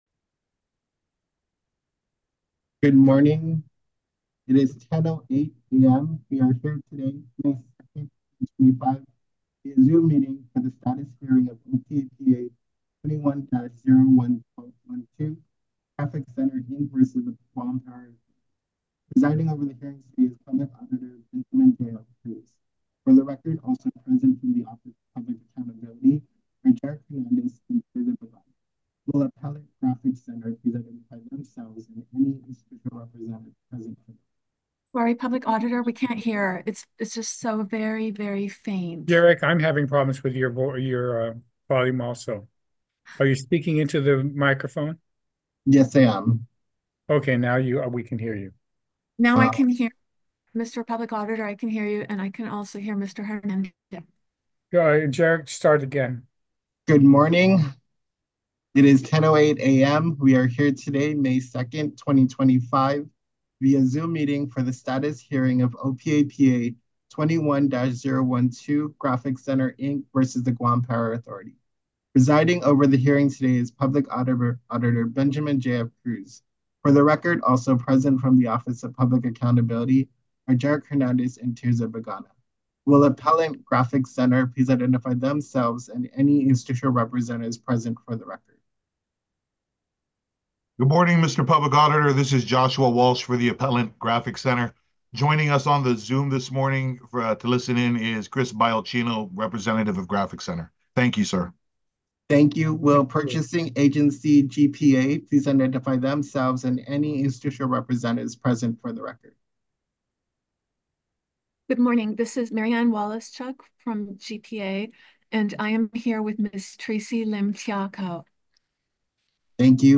Status Hearing - May 2, 2025